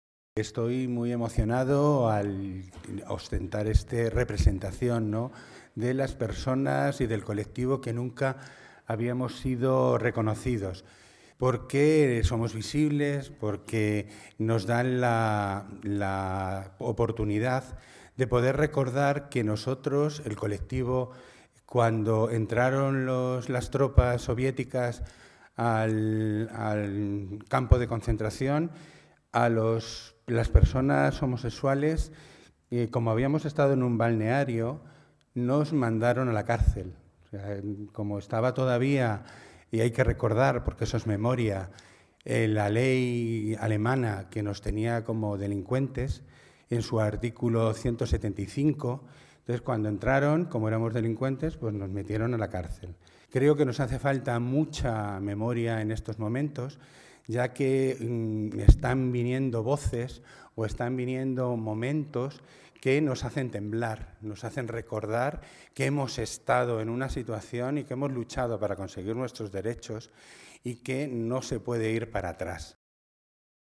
El Ayuntamiento de Madrid ha celebrado hoy, miércoles 23 de enero, en el Palacio de Cibeles, un acto conmemorativo con motivo del Día Internacional en Memoria de las Víctimas del Holocausto.